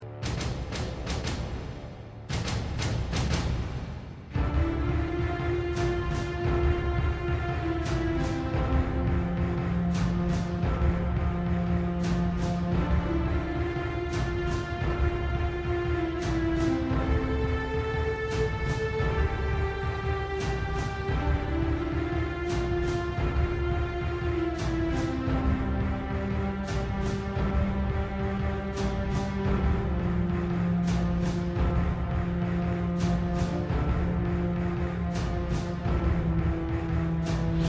• Качество: 128, Stereo
победные
Главная Тема